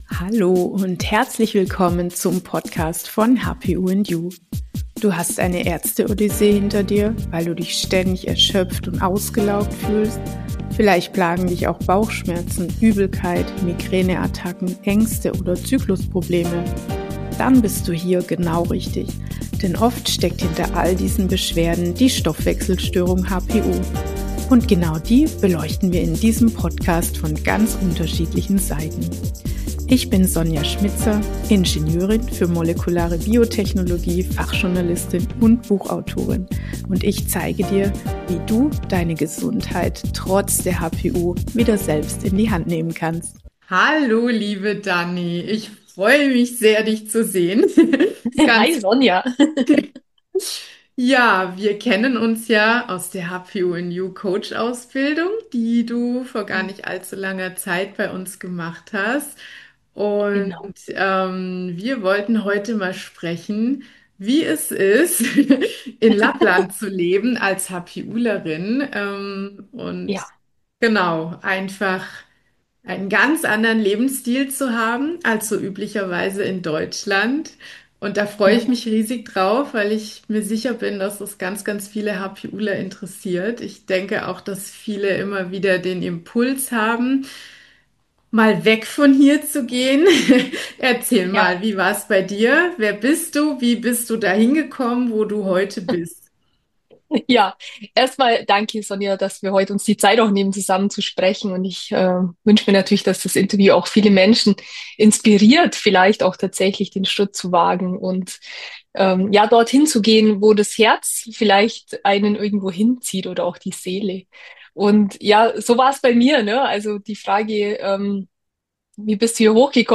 Auswandern mit oder wegen der HPU - Interview